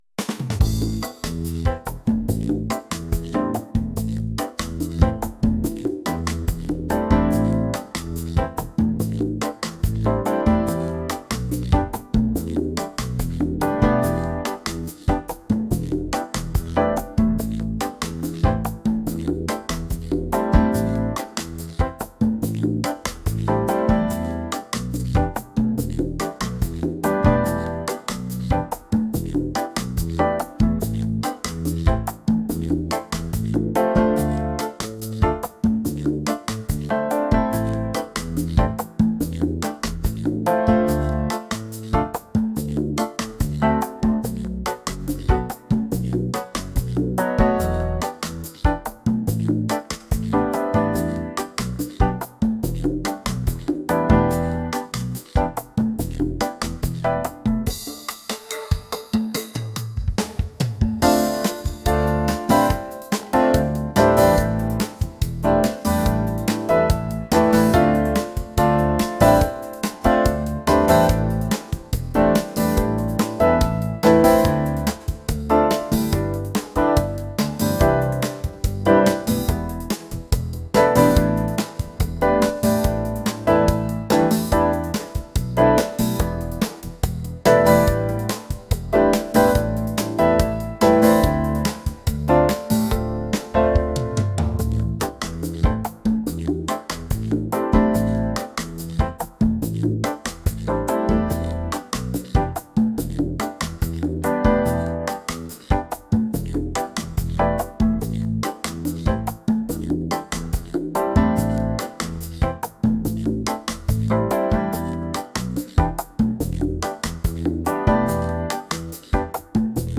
romantic | latin